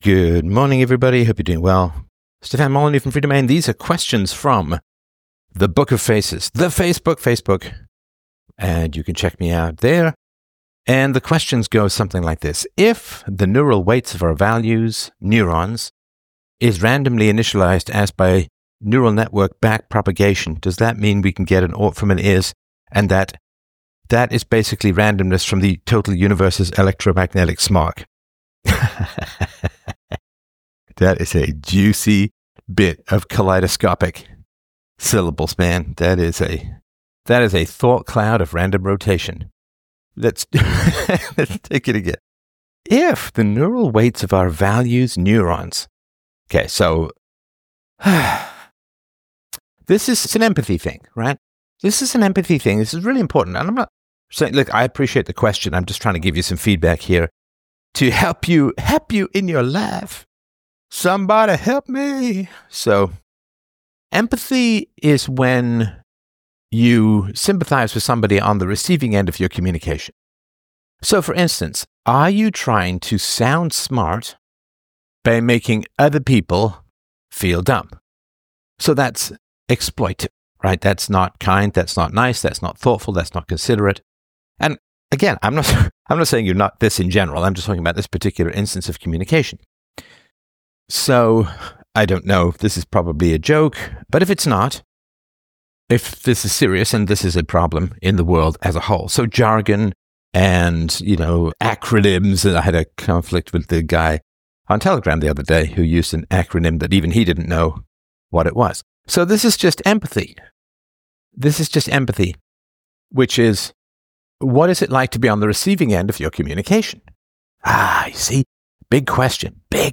5914 MY BROTHER ALMOST KILLED ME! Freedomain Call In – Freedomain with Stefan Molyneux – Lyssna här